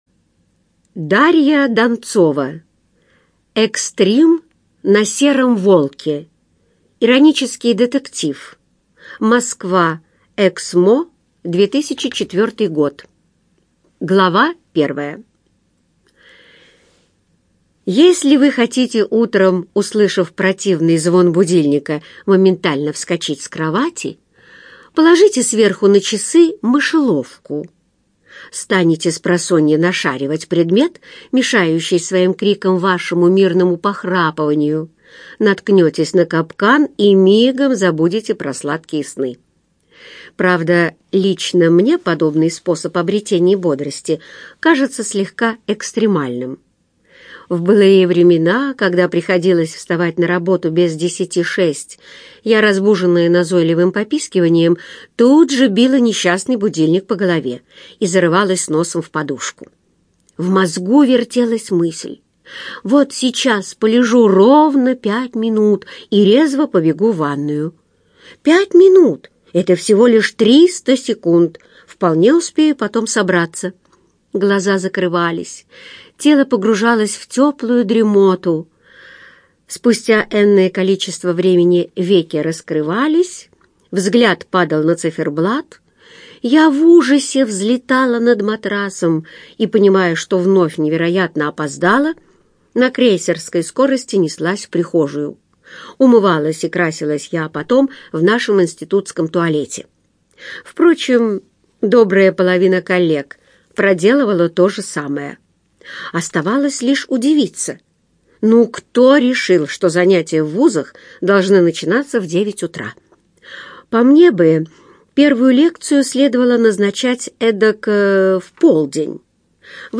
Аудиокнига Экстрим на сером волке - купить, скачать и слушать онлайн | КнигоПоиск